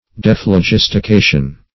De`phlo*gis`ti*ca"tion, n.